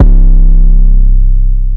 WV [808] wasted.wav